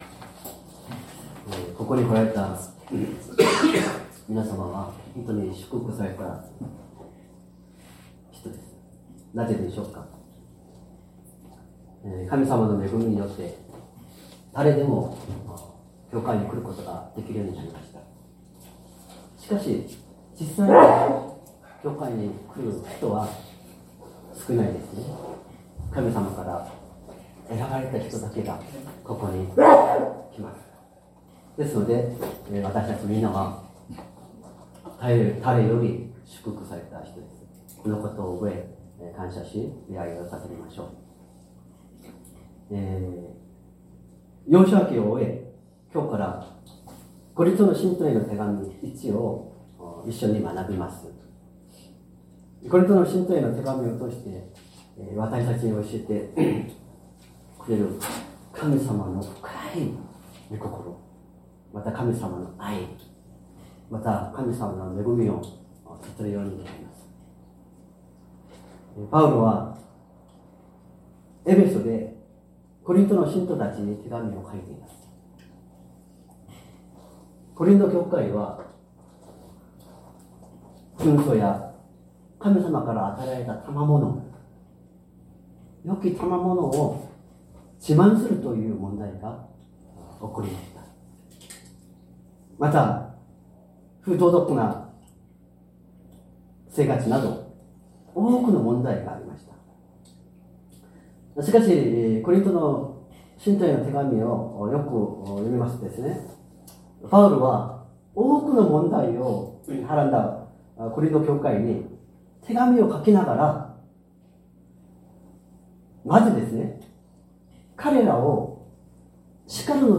善通寺教会。説教アーカイブ 2025年06月01日朝の礼拝「神は真実な方です」
音声ファイル 礼拝説教を録音した音声ファイルを公開しています。